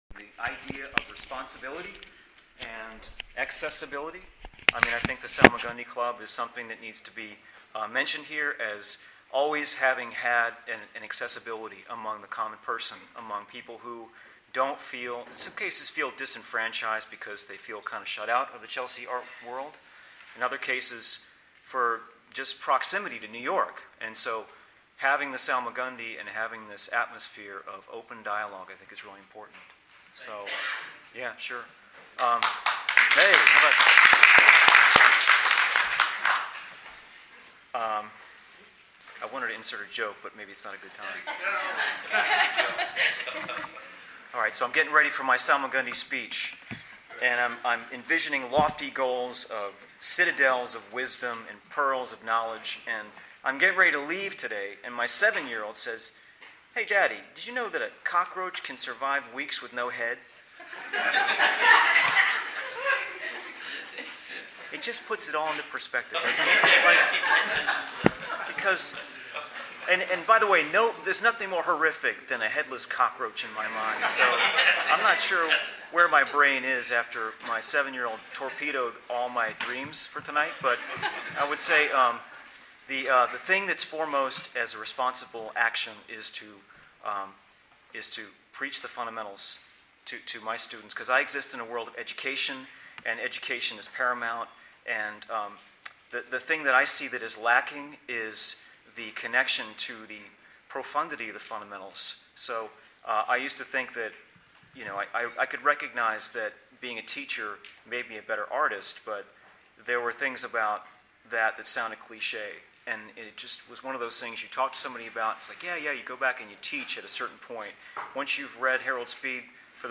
Panel Discussion: The Responsibility of the Artist in the 21st Century – PART 1